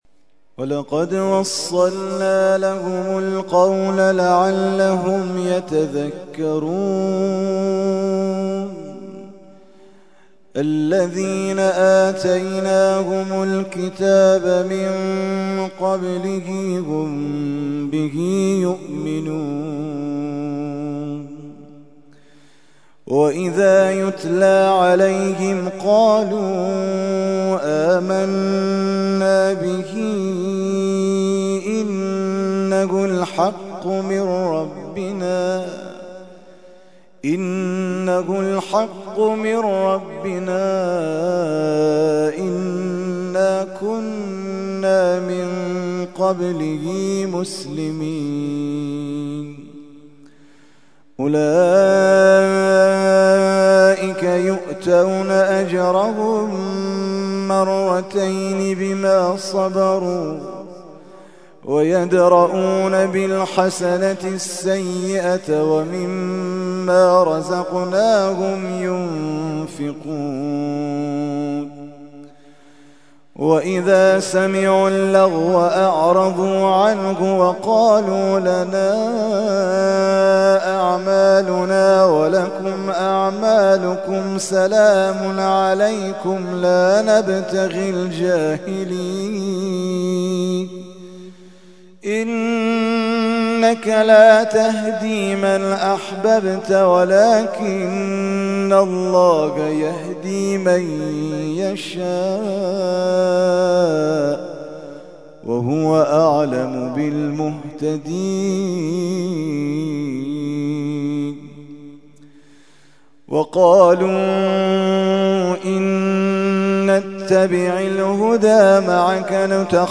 ترتیل خوانی جزء ۲۰ قرآن کریم در سال ۱۳۹۱